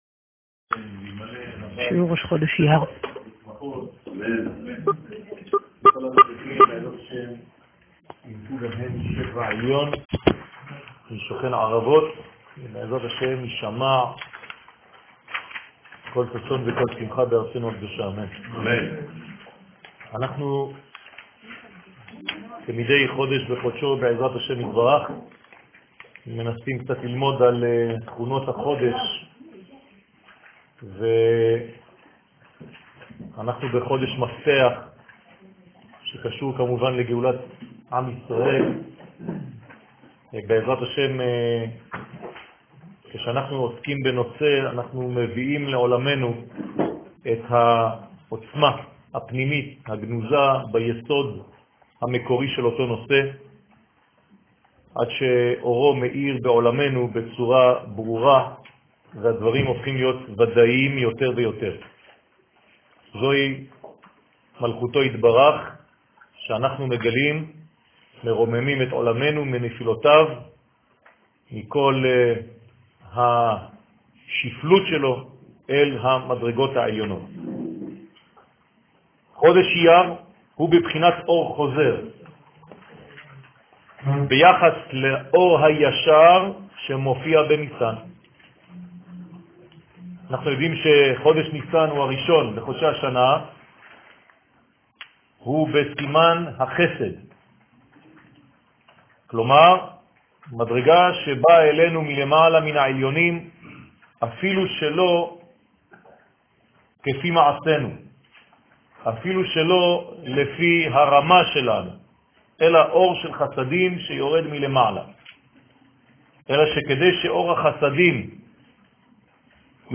שיעור ראש חודש אייר